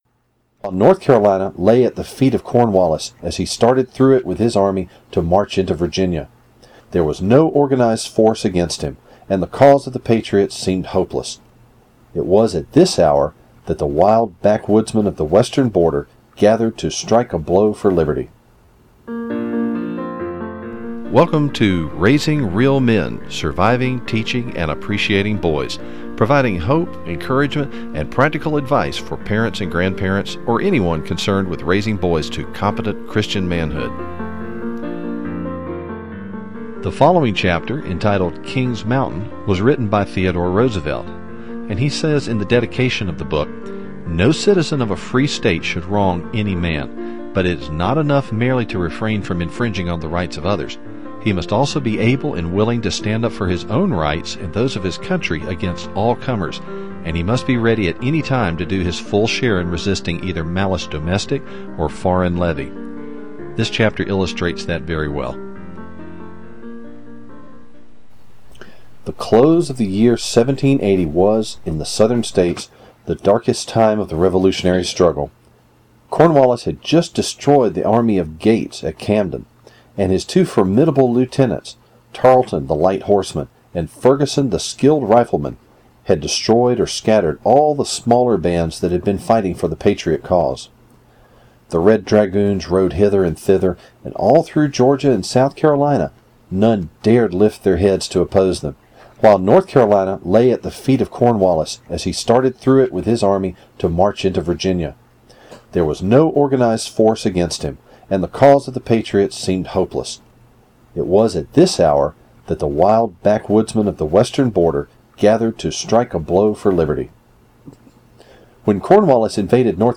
This week’s edition of the podcast is another reading from Hero Tales From American History.